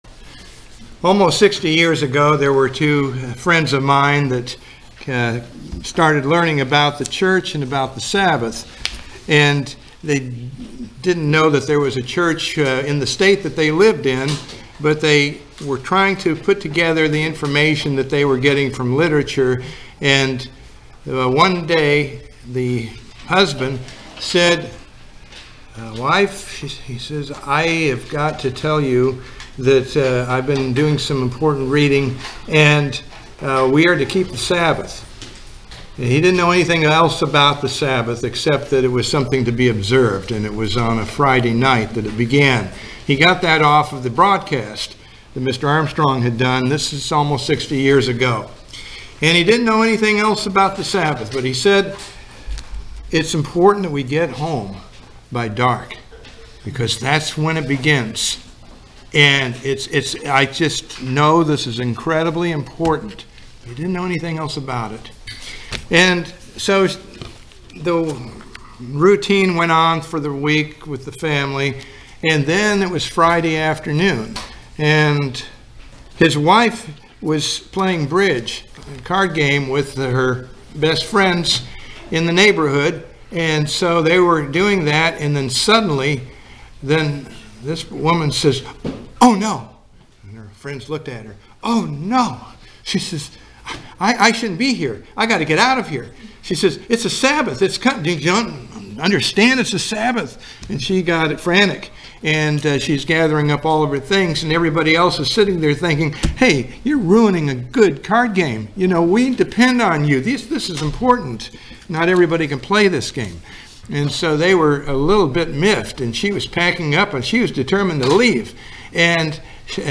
Sermons
Given in Ocala, FL